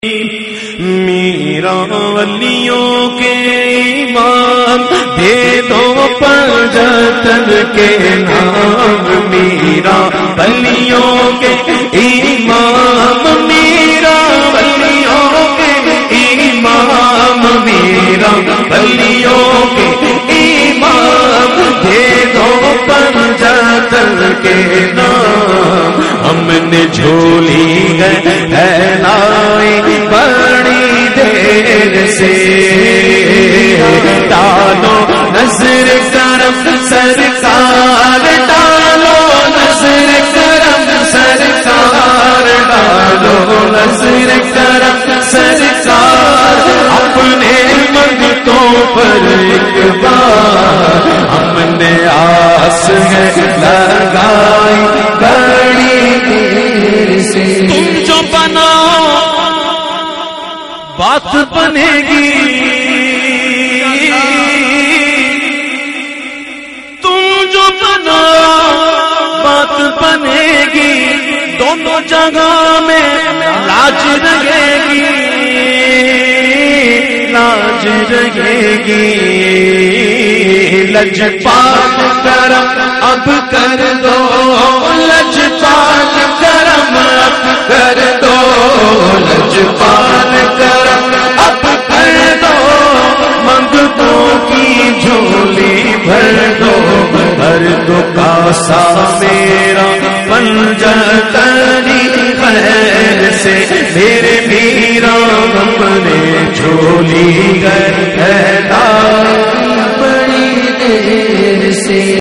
recited by famous Naat Khawan of Pakistan